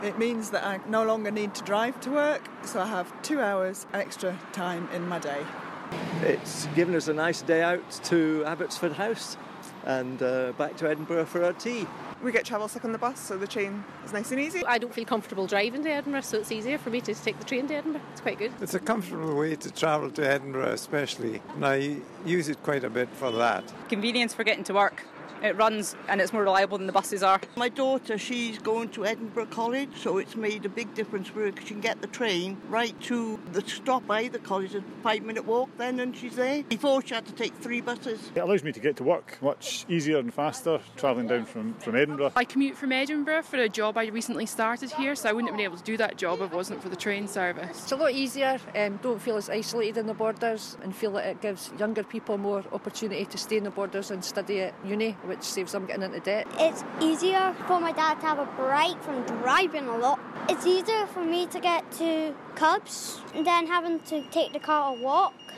LISTEN: The Borders Railway opened exactly three years ago today - we've been asking people in Galashiels what difference it's making to their lives...